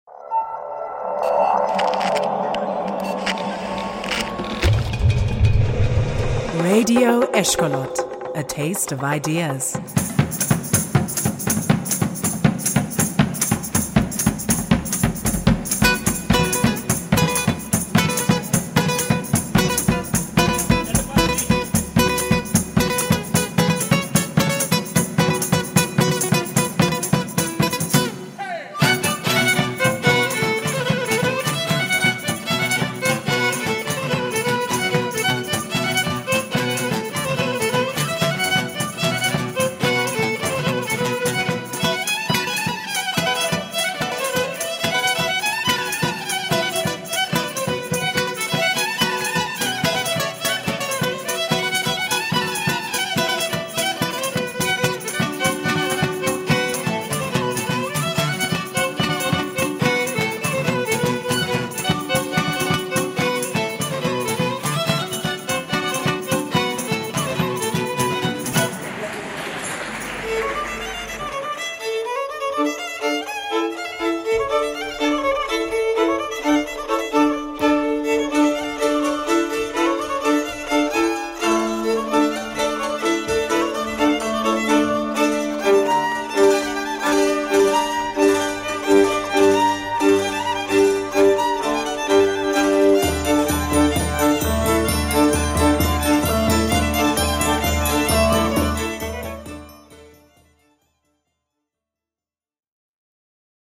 Аудиокнига Принуждение к танцу / Compulsion to Dance | Библиотека аудиокниг